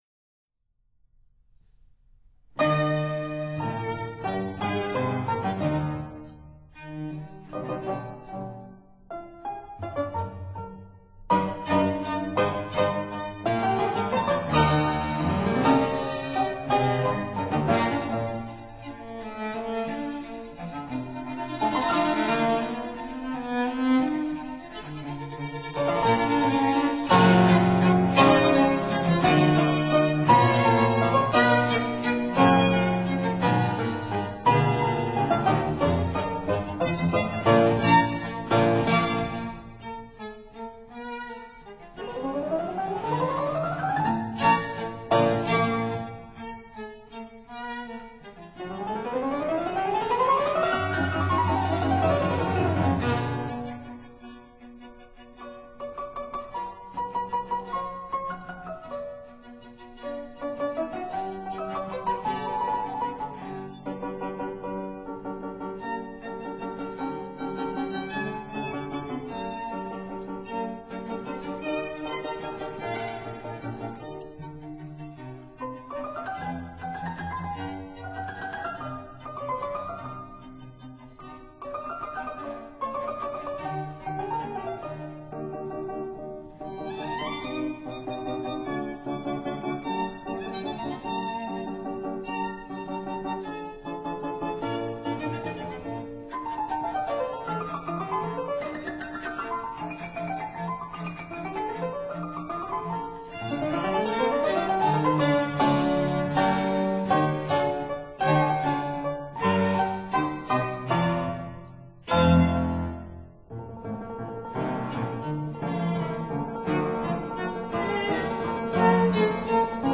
Allegro moderato
Fortepiano
Violin
Cello
Period Instruments